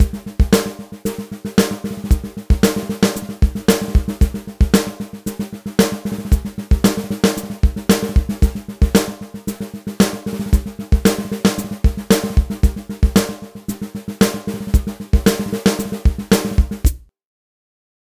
А прикольно звучит! Только к нему добавлять нужно сэмплы ударов с сильной атакой, или римшоты. Вложения Exite Snare Drum.mp3 Exite Snare Drum.mp3 709,2 KB · Просмотры: 7.599